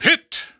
The announcer saying Pit's name in English and Japanese releases of Super Smash Bros. Brawl.
Pit_English_Announcer_SSBB.wav